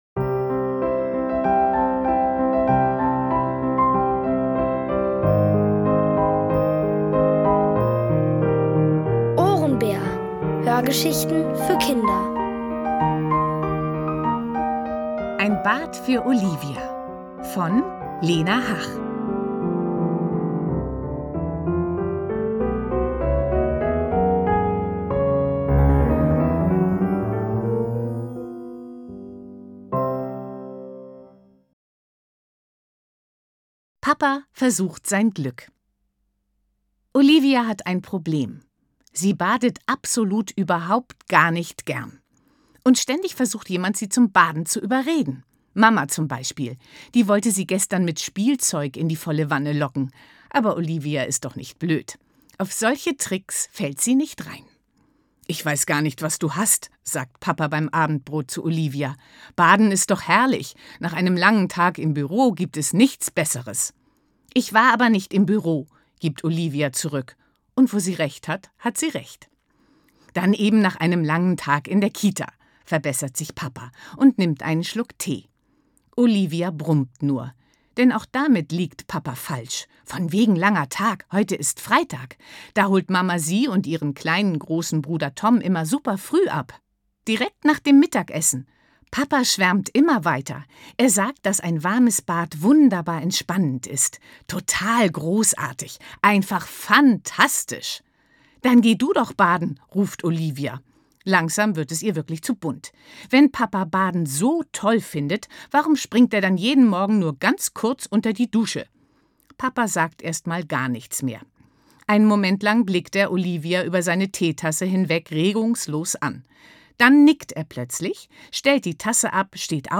Von Autoren extra für die Reihe geschrieben und von bekannten Schauspielern gelesen.
Kinder & Familie